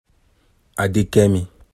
Captions English Audio pronunciation of Adekemi
Yo-Adekemi.ogg